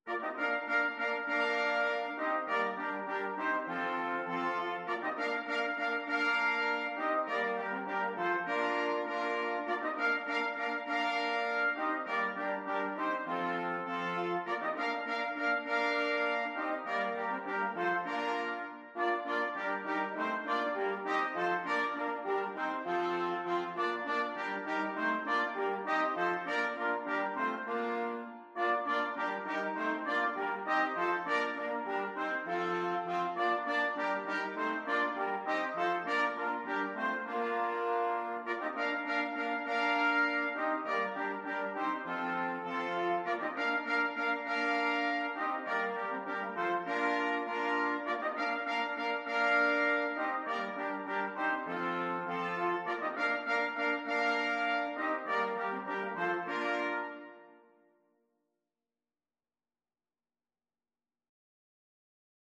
Free Sheet music for Brass Quartet
Trumpet 1 Trumpet 2French HornTrombone
4/4 (View more 4/4 Music)
Bb major (Sounding Pitch) (View more Bb major Music for Brass Quartet )
Classical (View more Classical Brass Quartet Music)